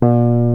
JAZZGUITAR 6.wav